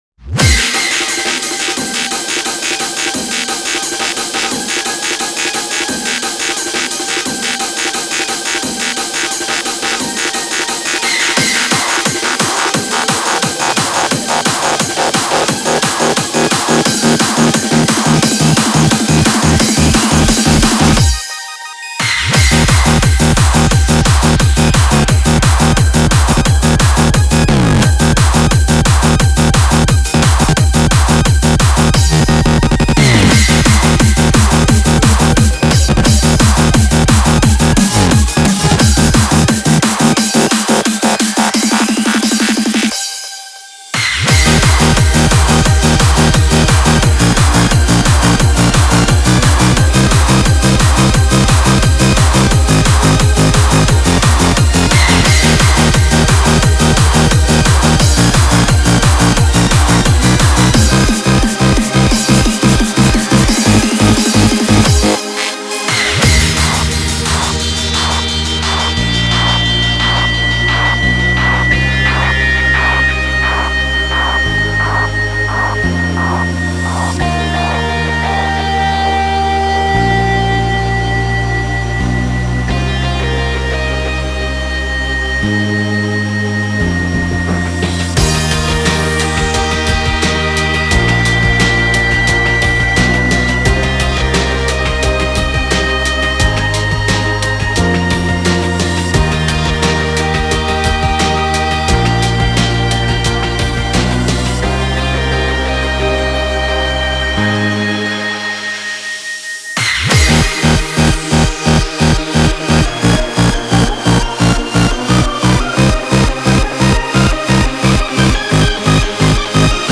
data/localtracks/Japanese/J-Core